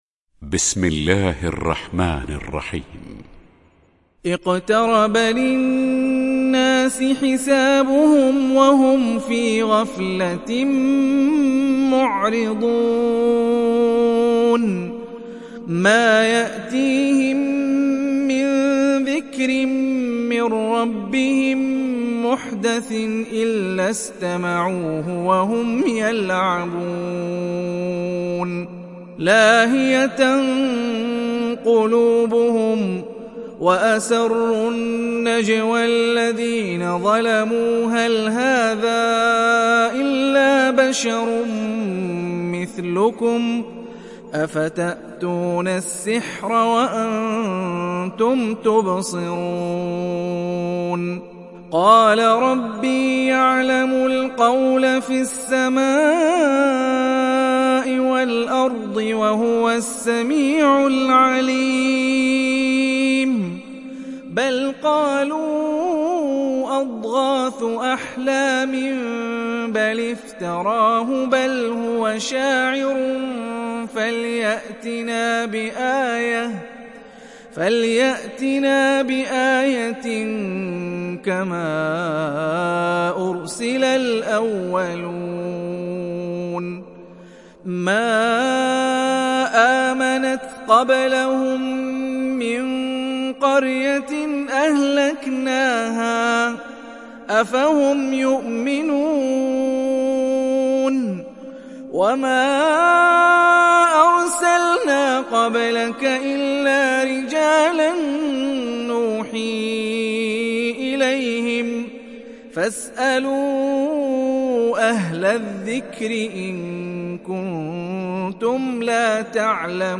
Surah Al Anbiya Download mp3 Hani Rifai Riwayat Hafs from Asim, Download Quran and listen mp3 full direct links